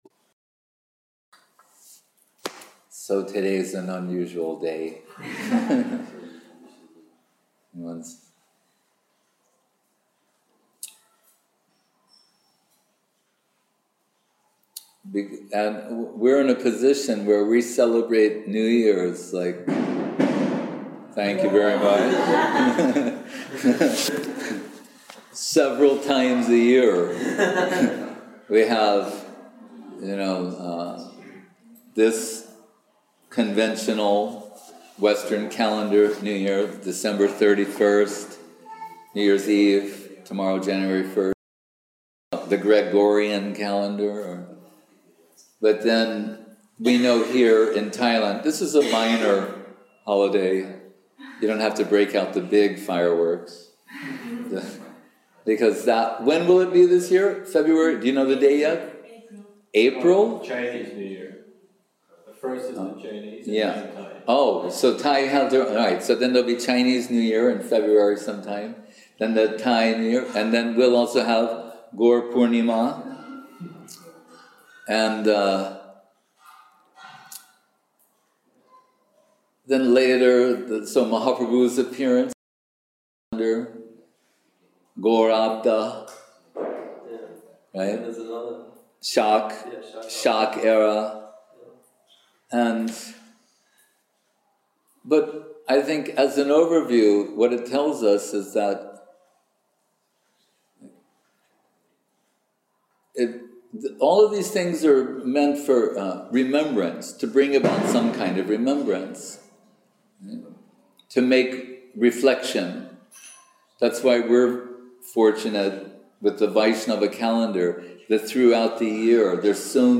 New Year speech